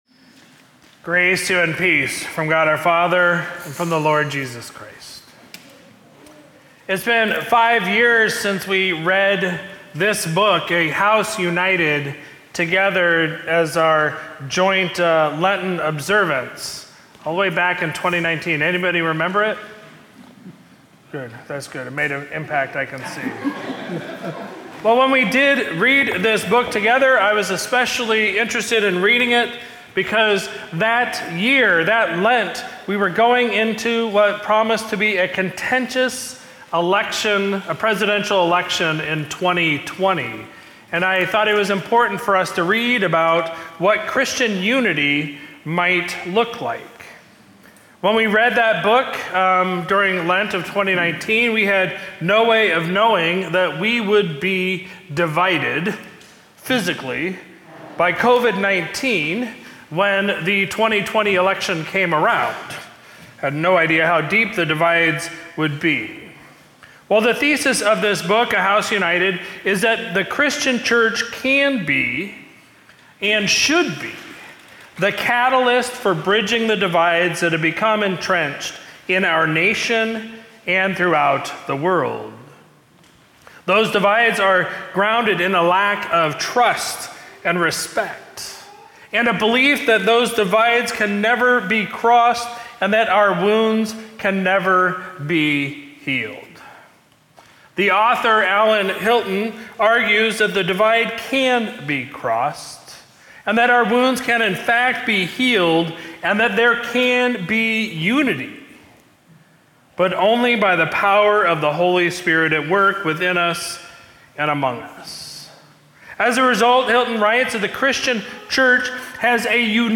Sermon from Sunday, April 28, 2024